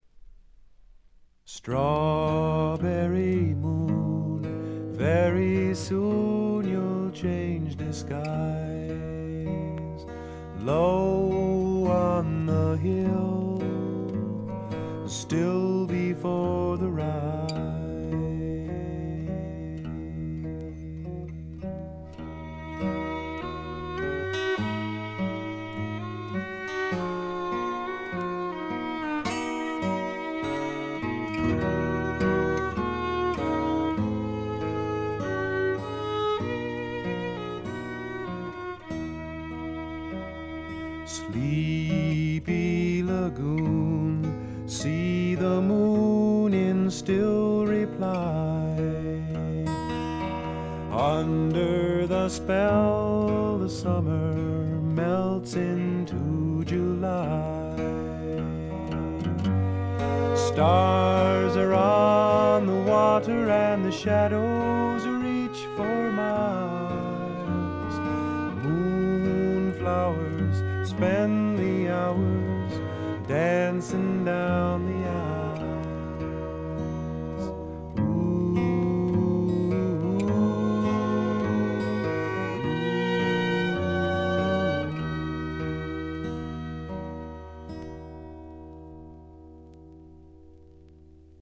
微細なチリプチわずか。
全体に静謐で、ジャケットのようにほの暗いモノクロームな世界。
試聴曲は現品からの取り込み音源です。